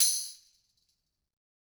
Tamb1-Hit_v2_rr2_Sum.wav